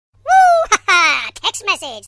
Kategorien: Lustige